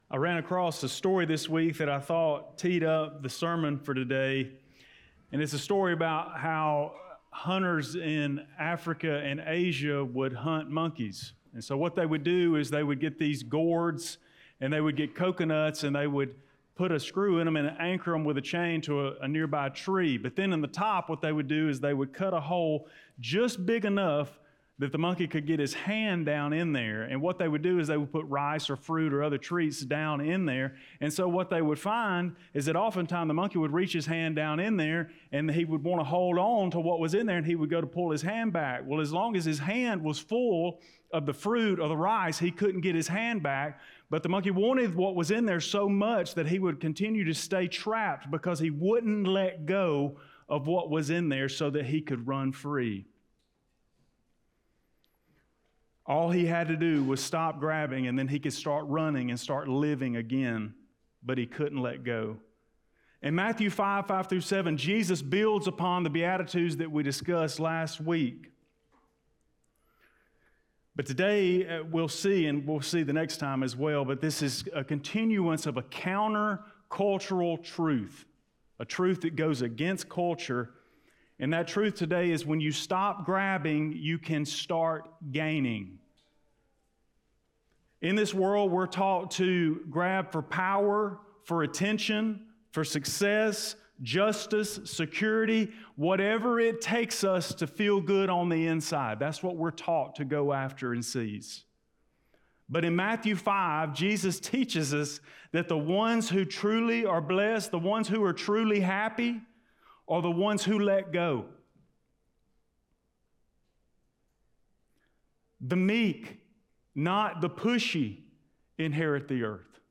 Blount Springs Baptist Church Sermons